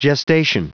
Prononciation du mot gestation en anglais (fichier audio)
Prononciation du mot : gestation